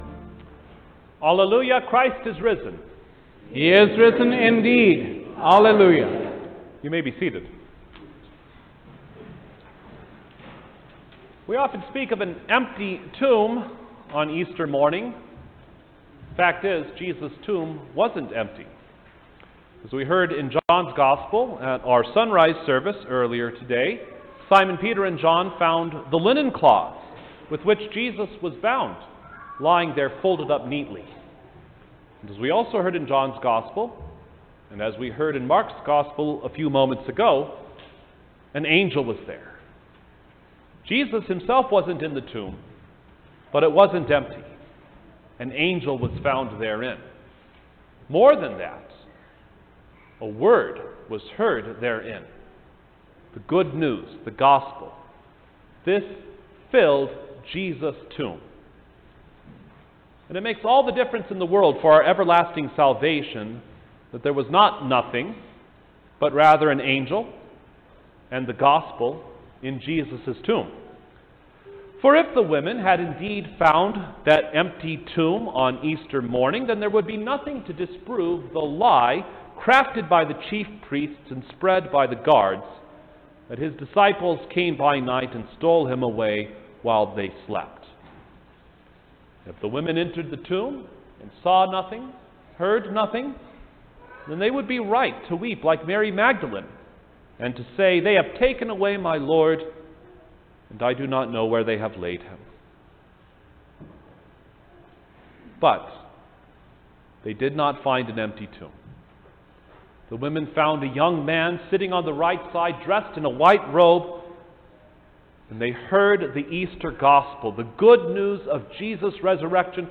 Easter Chief Service